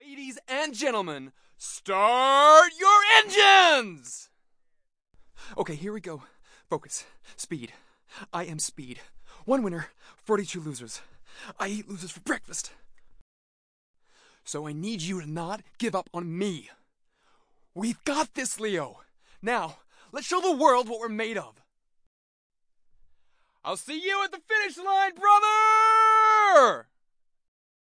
Voice Reel
US - Video Game Reel